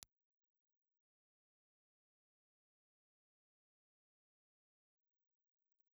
Impulse Response file of the SR1 ribbon microphone.
Reslo_SR1_IR.wav
They are nice sounding figure-8 ribbon microphones, and are very much less common than the RBs.